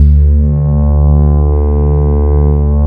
DIGI PHAT 2.wav